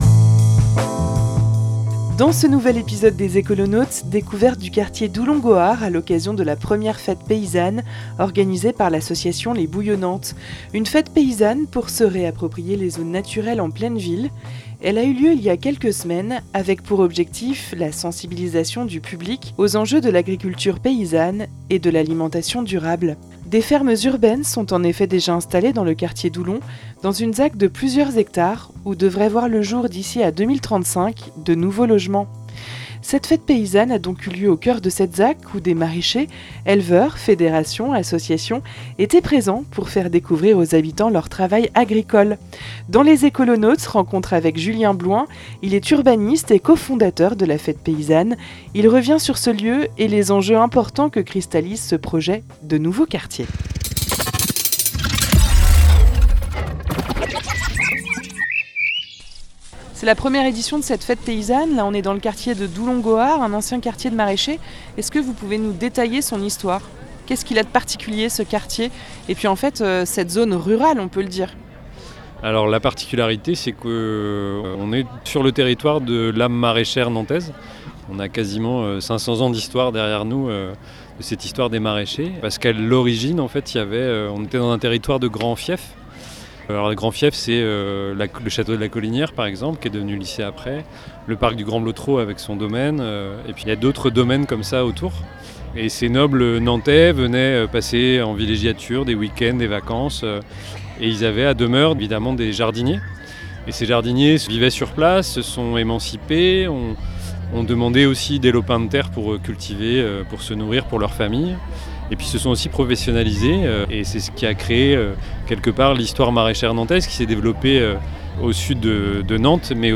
Dans ce premier épisode de la saison, direction la 1ère fête paysanne dans le quartier Doulon - Gohards. Un quartier en pleine nature qui cristallise les tensions puisqu'un projet immobilier est prévu à l'horizon 2035.